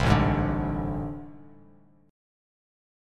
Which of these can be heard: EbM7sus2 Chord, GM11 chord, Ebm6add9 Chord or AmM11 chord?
AmM11 chord